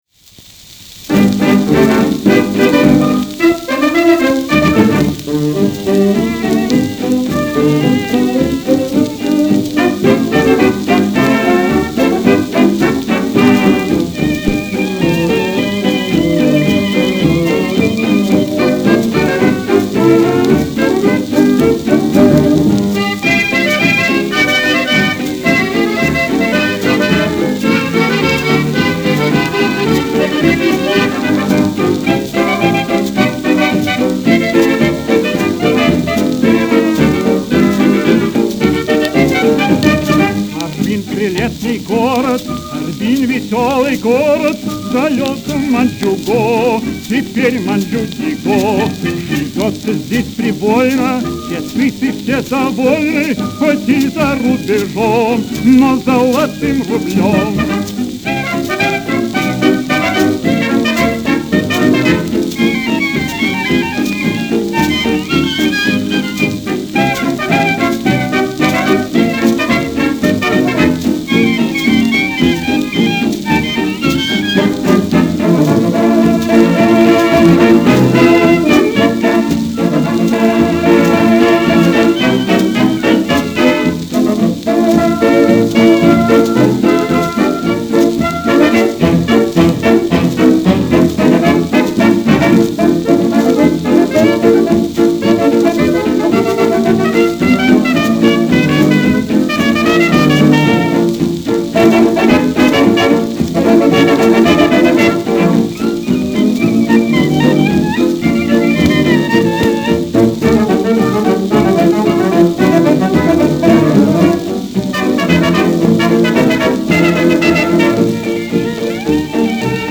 Популярный в 30-е годы фокстрот.